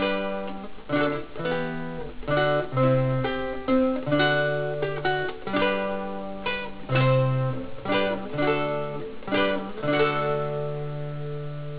LUTE
It is immediately recognisable by its distinctive bowl shaped back, short neck and sharply angled-back head.
Lute Sound Clips